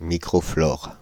Ääntäminen
Synonyymit microbiote Ääntäminen France (Île-de-France): IPA: /mi.kʁɔ.flɔʁ/ Haettu sana löytyi näillä lähdekielillä: ranska Käännös Substantiivit 1. microflora Suku: f .